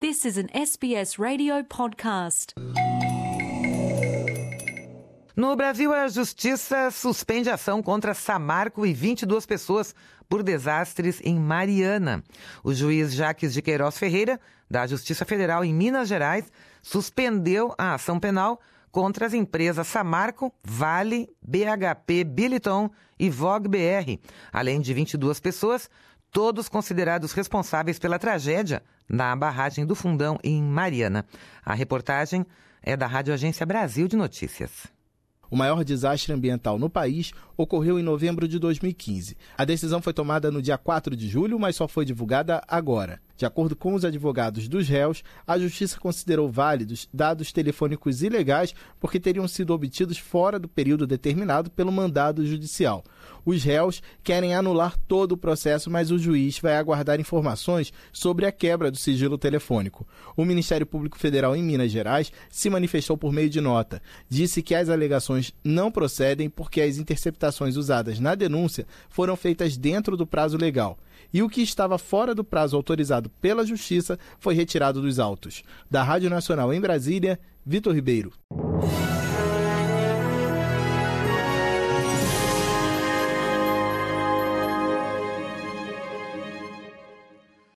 Reportagem da Radioagência Brasil de notícias.